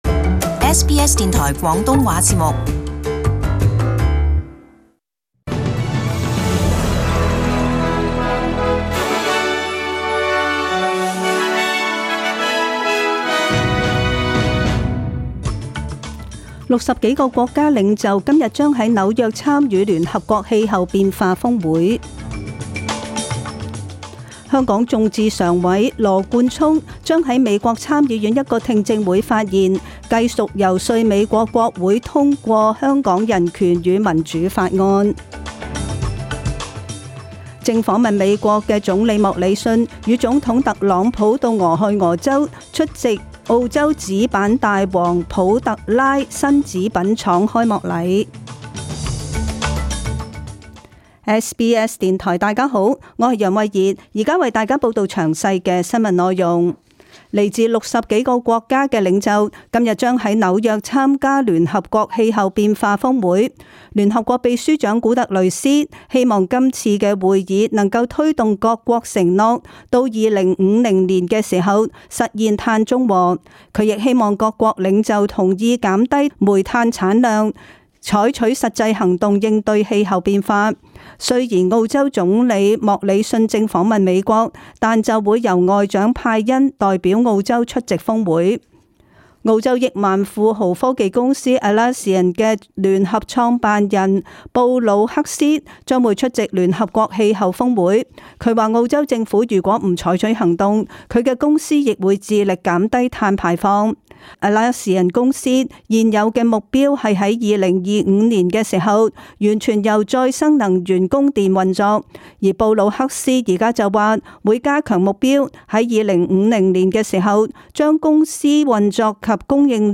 Detailed morning news bulletin.